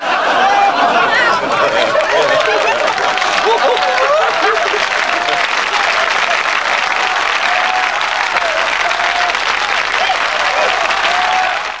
Beifall
Category: Television   Right: Personal